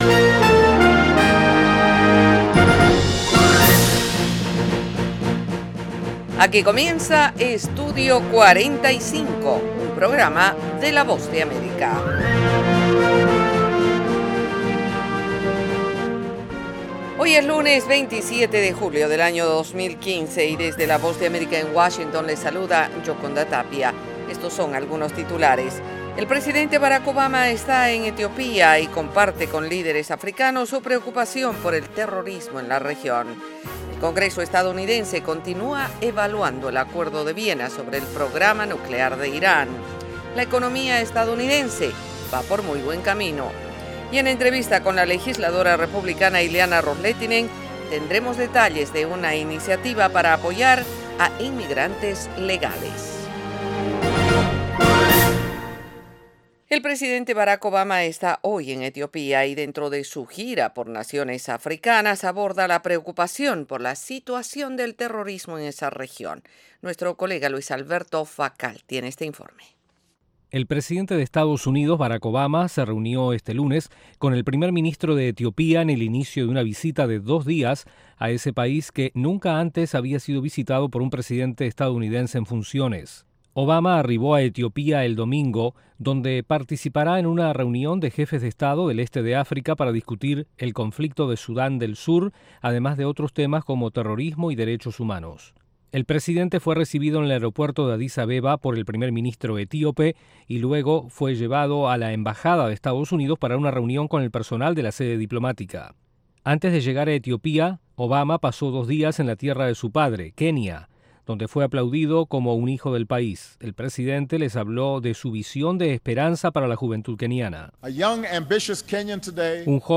Treinta minutos de la actualidad noticiosa de Estados Unidos con análisis y entrevistas.